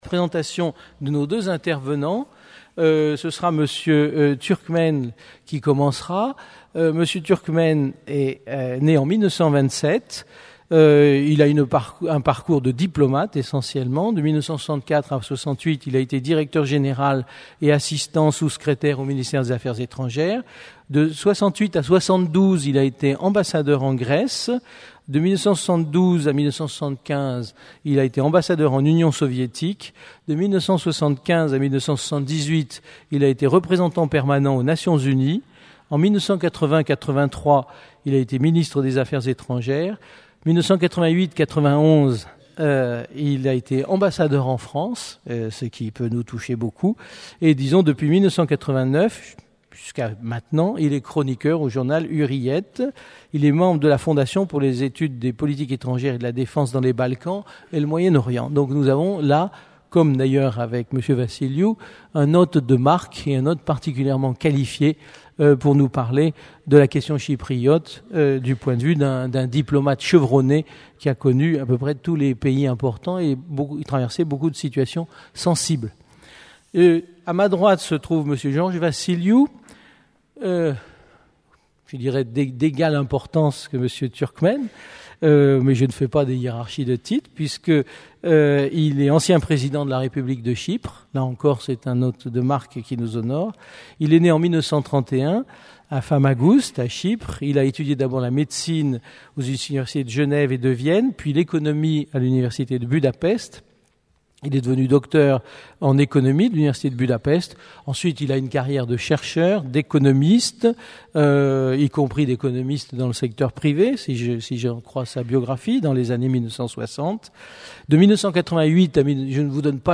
La question chypriote par M. Ilter Turkmen, Ancien Ministre des Affaires étrangères et M. Georges Vassiliou, Ancien Président de la République Chypriote Une conférence du cycle La Turquie : aujourd'hui, demain